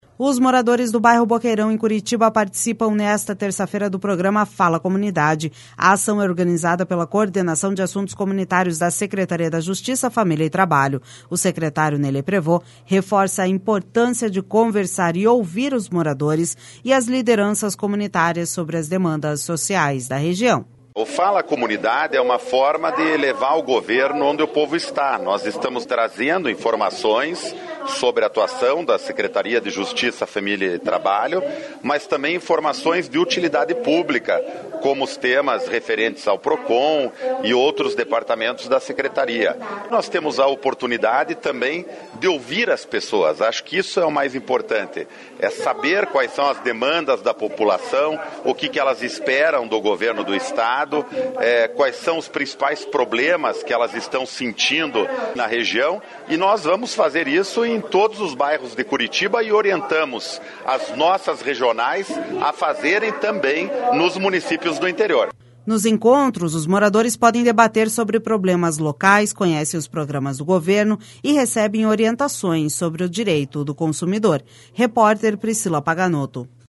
O secretário Ney Leprevost reforça a importância de conversar e ouvir os moradores e as lideranças comunitárias sobre as demandas sociais da região.// SONORA NEY LEPREVOST//Nos encontros, os moradores podem debater sobre problemas locais, conhecem os programas do governo e recebem orientações sobre o direito do consumidor.